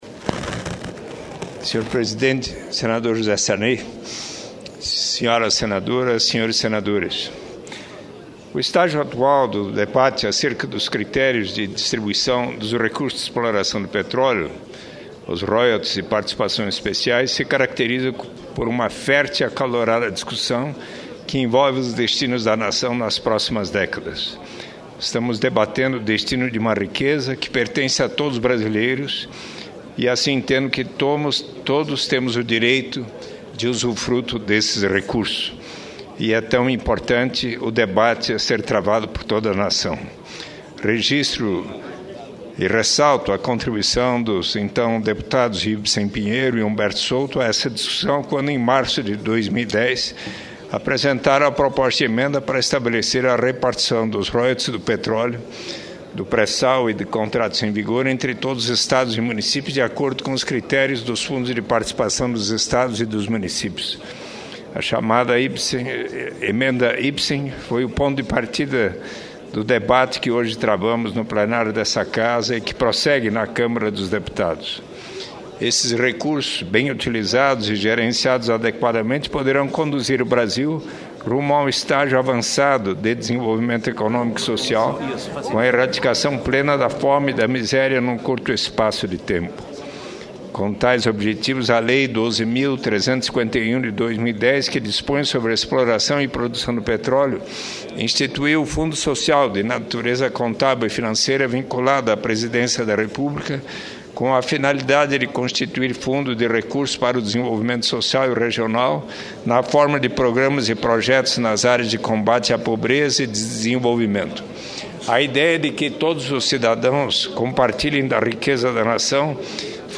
Pronunciamento do senador Eduardo Suplicy